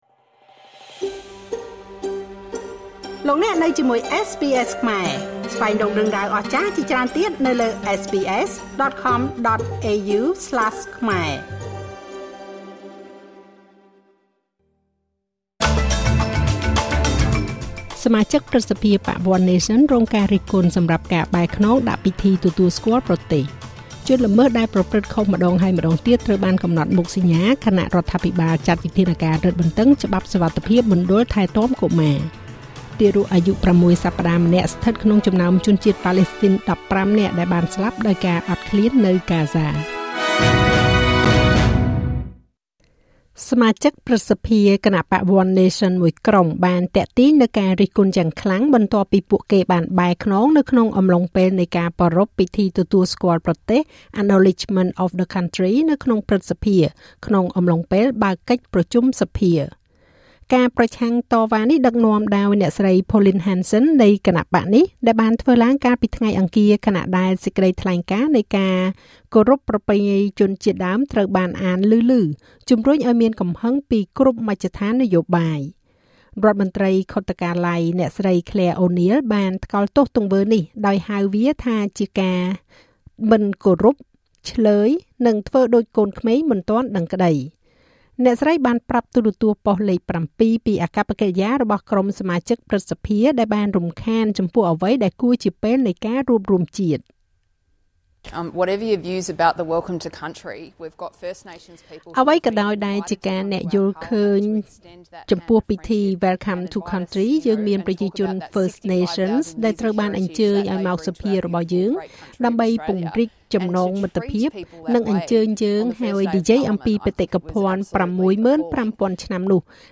នាទីព័ត៌មានរបស់SBSខ្មែរ សម្រាប់ថ្ងៃពុធ ទី២៣ ខែកក្កដា ឆ្នាំ២០២៥